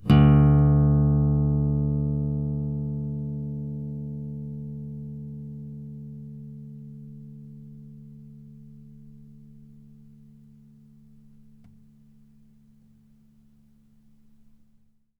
bass-16.wav